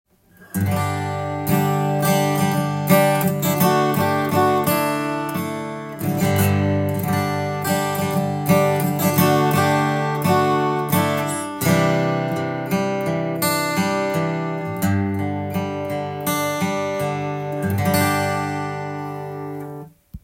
当教室でいつも使っているギルドのアコースティックギターがあります。
フレット交換前
これだと押さえにくいですし、正確にチューニングしても音程が狂ってしまいます。
フレット交換前は少し、疲れた音がしていたのですが